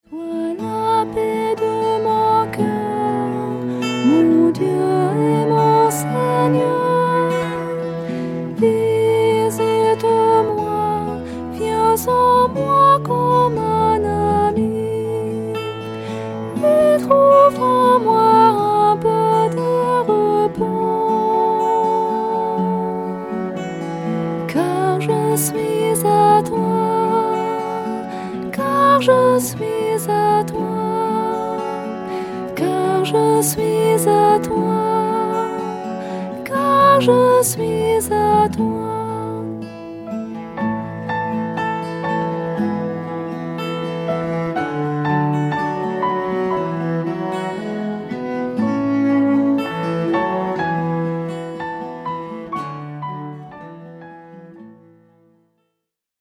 Usage : Adoration-Confiance
MIDI 4 voix